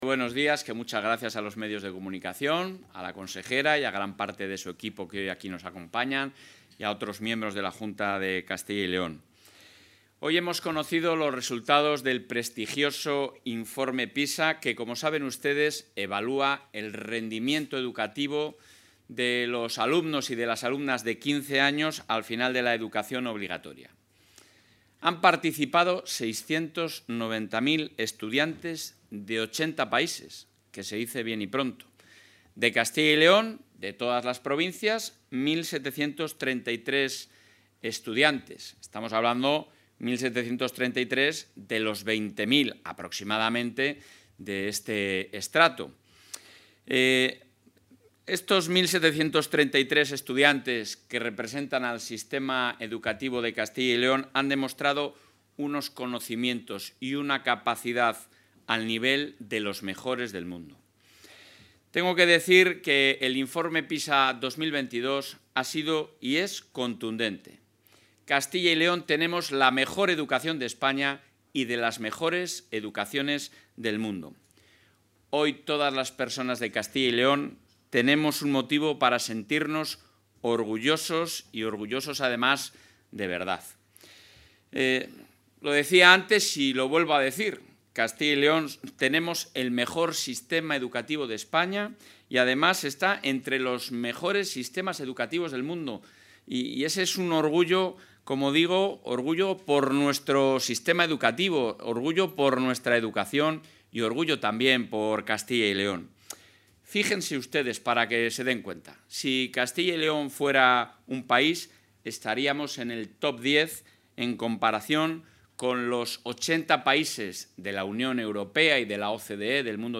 Intervención del presidente de la Junta.